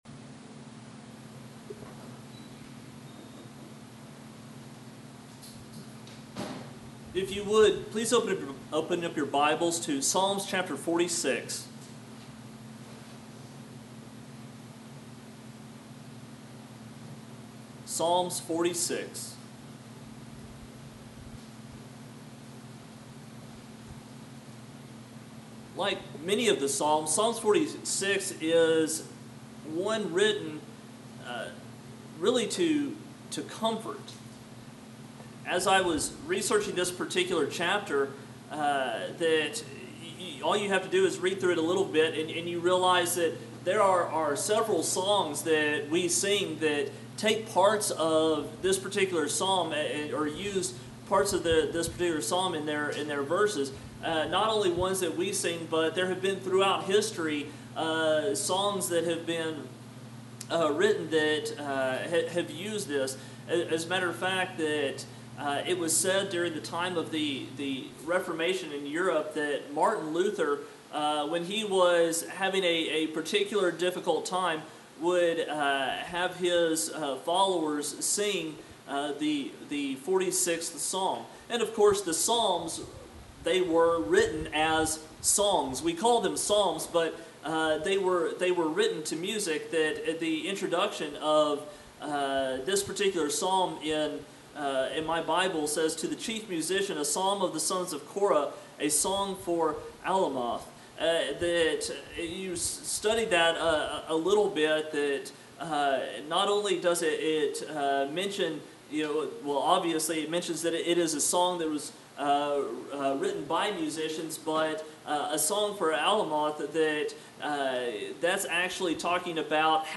Sermon on Be Still & Know I Am God